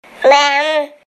Jack-jack’s Vocal (the Incredibles) Sound Effect Free Download
Jack-jack’s Vocal (the Incredibles)